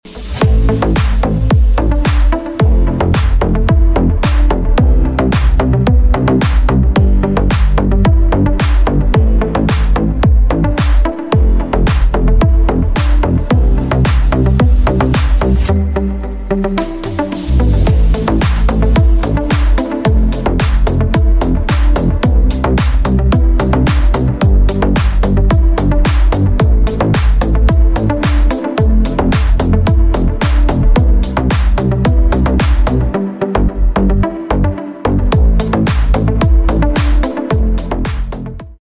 Plastic Profile Extrusion Line |